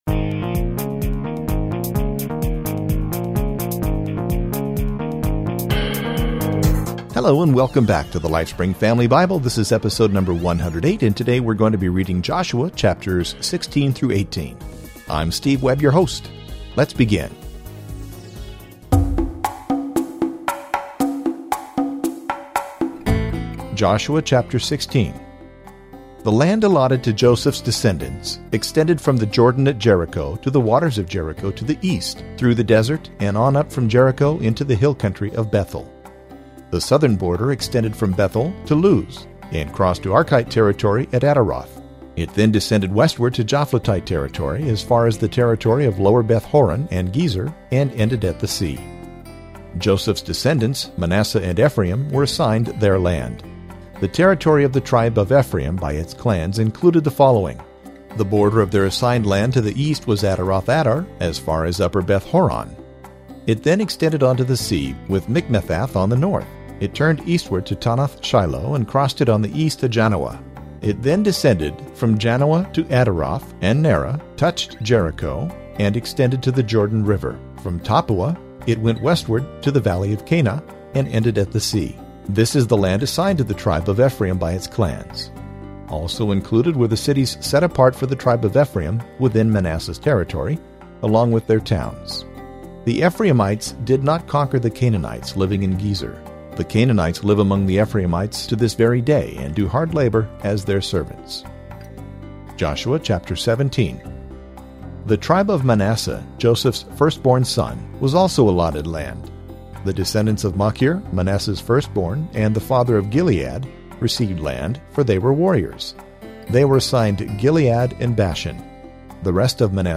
Lifespring! Family Audio Bible